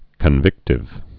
(kən-vĭktĭv)